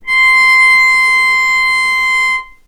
vc-C6-mf.AIF